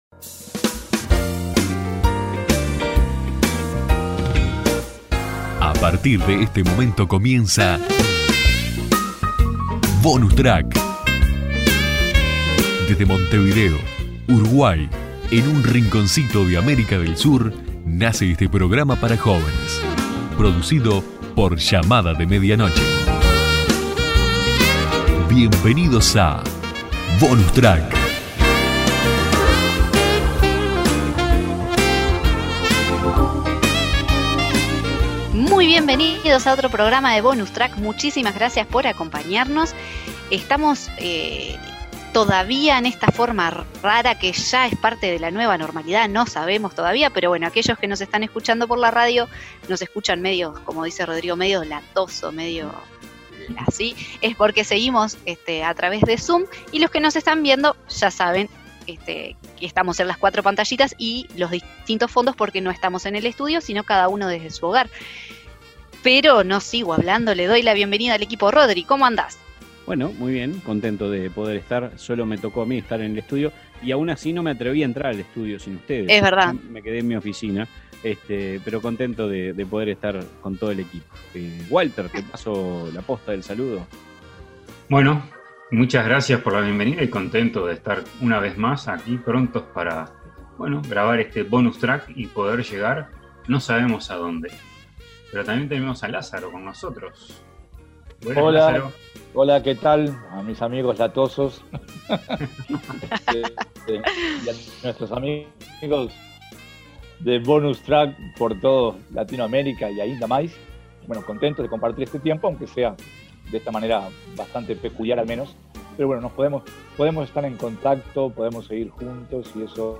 Bonus Track de hoy presenta: El discipulado I. Un programa para meditar, aprender y disfrutar de una conversación amena.
Bonus Track, te invita a que te prendas en sintonía durante 28 minutos para compartir un poco de todo: opiniones, invitados, un buen tema de conversación y la buena música, que no debe faltar. El tema de hoy “El discipulado”.